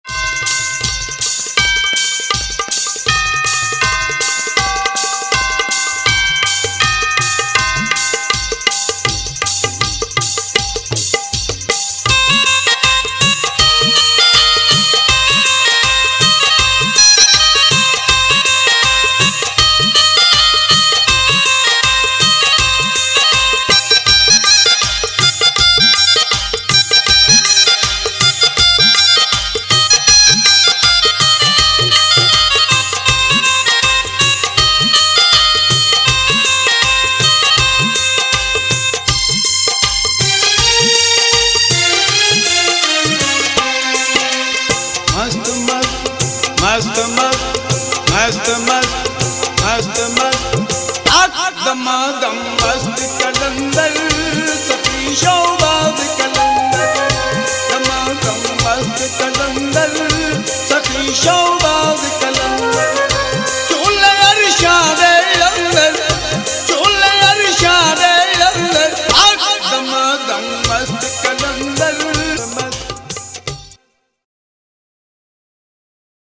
Sufi Qawwali